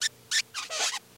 Marker Drawing Squeak